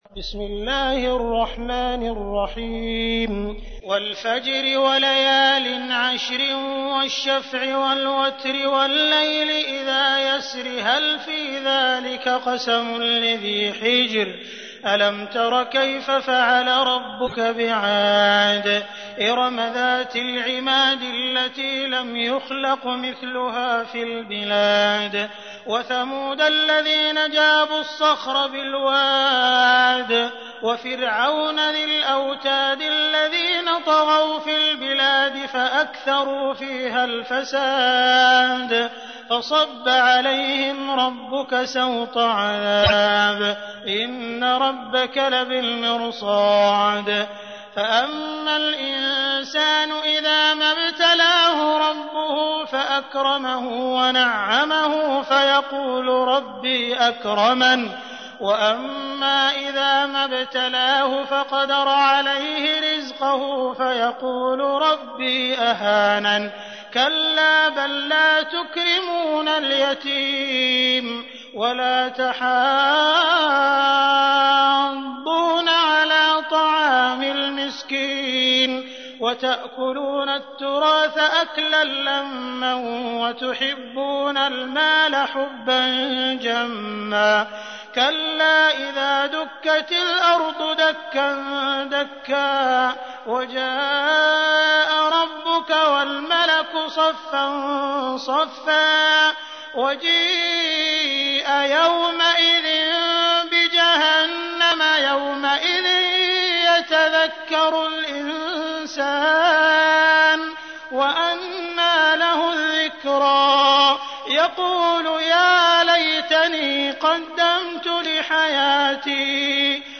تحميل : 89. سورة الفجر / القارئ عبد الرحمن السديس / القرآن الكريم / موقع يا حسين